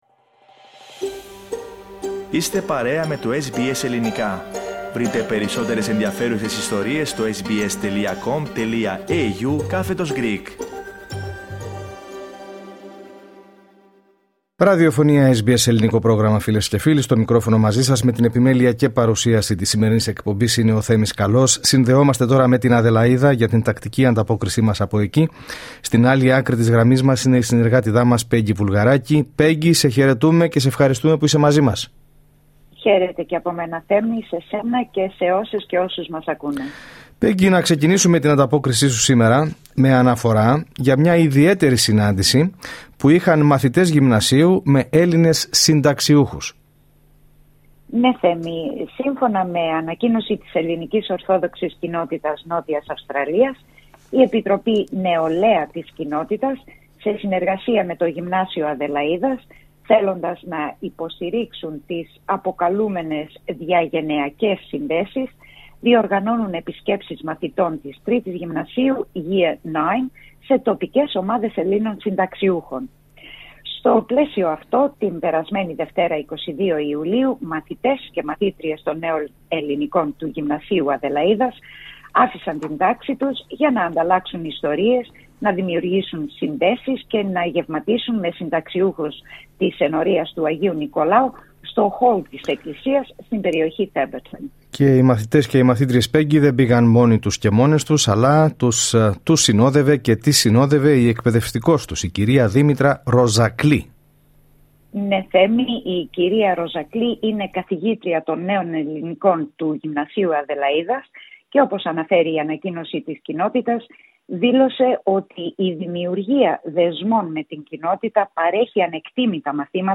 Ακούστε την εβδομαδιαία ανταπόκριση από την Αδελαΐδα